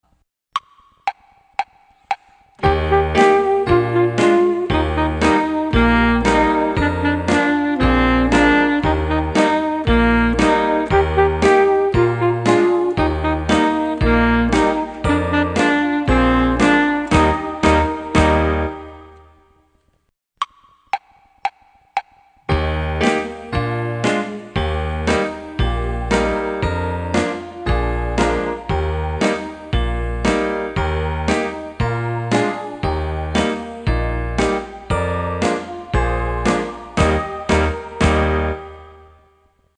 According to the gentleman, the Good Humor melody used in Southern California was based on a Polish (some sources say Czechoslovakian) folk melody called "Stodola Poompa" or "Stodola Pumpa".
Here's a link to an MP3 of the traditional melody "Stodola Pumpa" (Quicktime plugin required):